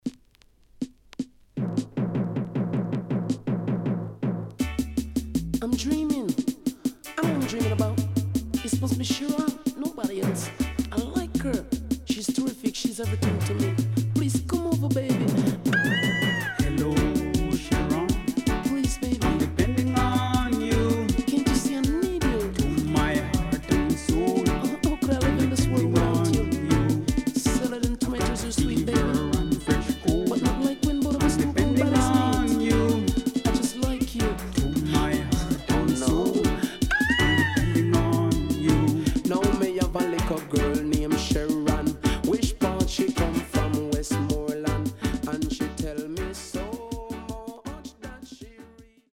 HOME > DISCO45 [DANCEHALL]  >  COMBINATION
SIDE A:少しチリノイズ、プチノイズ入ります。